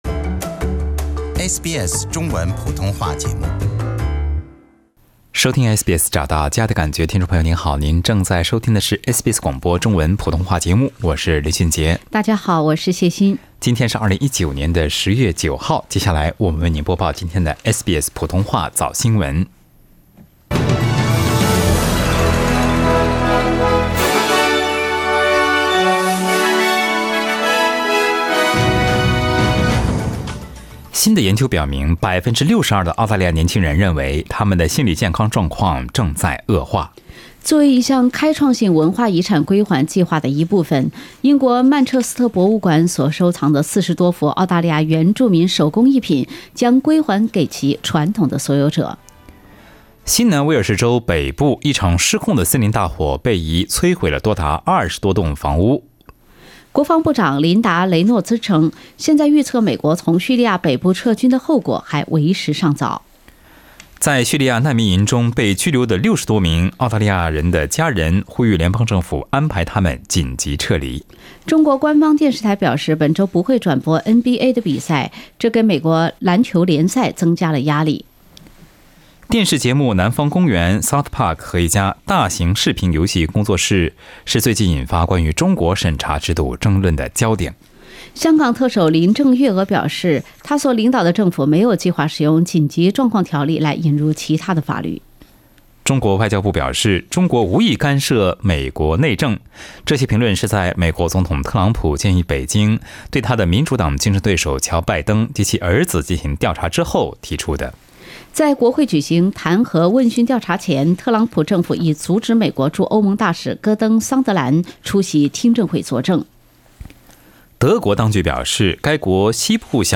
SBS早新闻 （10月9日）